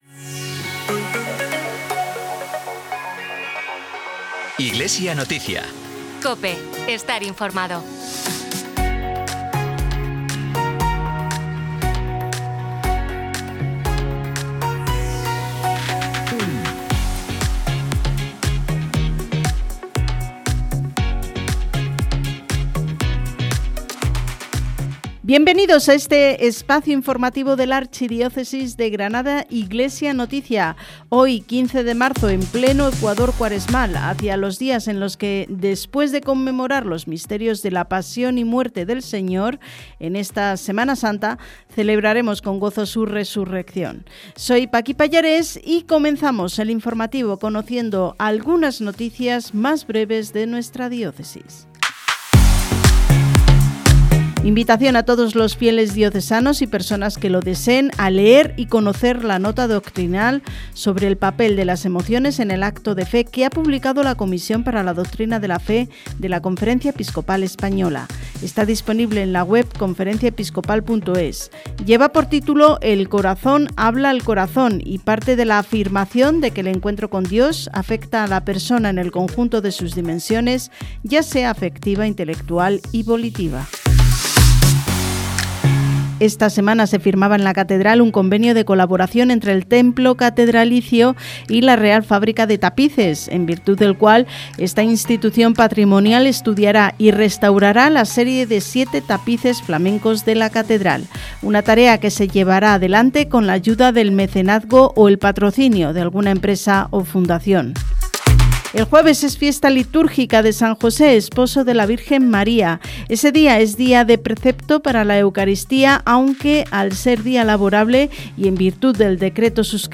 Programa emitido en COPE Granada y COPE Motril, el 15 de marzo de 2026